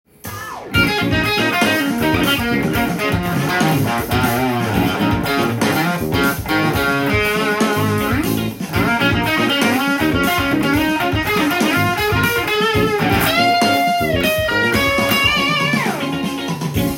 次にブルージーなソロを弾いてみました。
B♭ｍペンタトニックスケールを使用してみました。
ソロがマンネリ化してしまうので、少し難しそうに聞こえるように工夫しました。
pentatonic.solo_.m4a